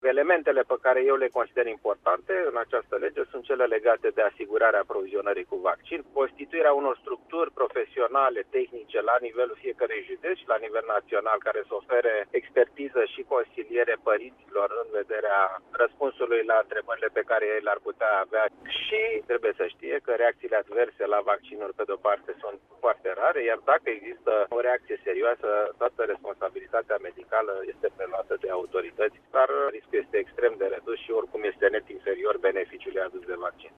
Consilierul ministrului Sănătăţii, Alexandru Rafila, a precizat pentru Radio România Actualităţi, că legea îşi propune să crească rata vaccinării în România.